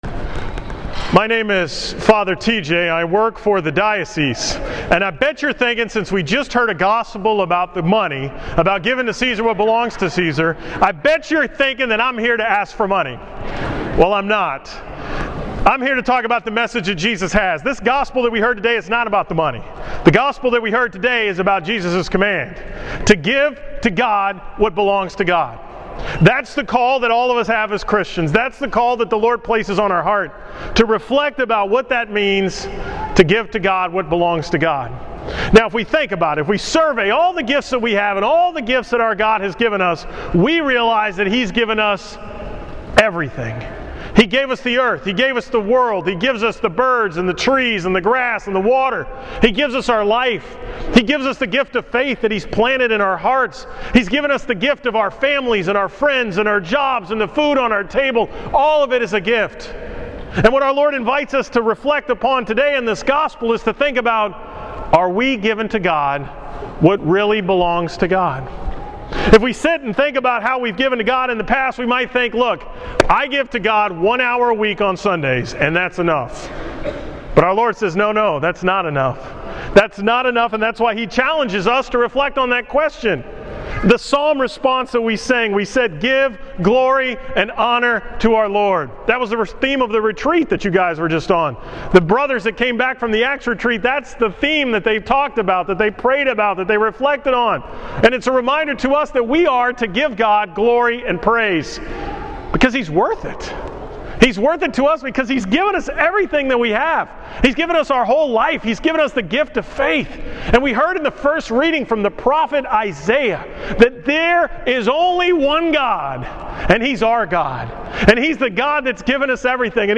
From the 10:45 am Mass at St. Bartholomew on October 19, 2014